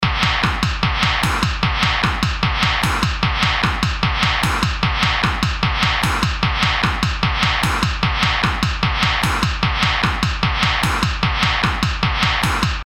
Cycling.wav